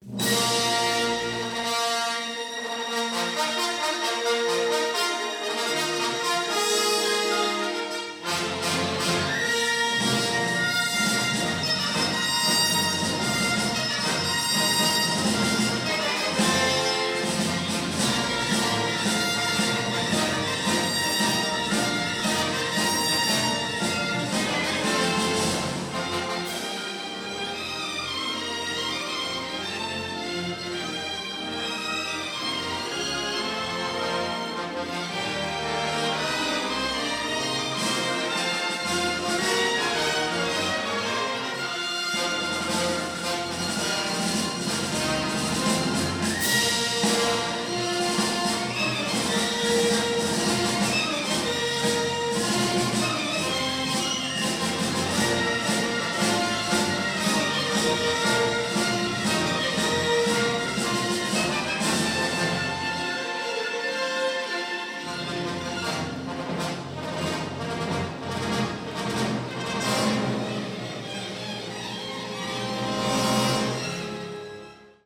Live!